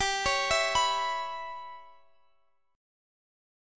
Listen to G6b5 strummed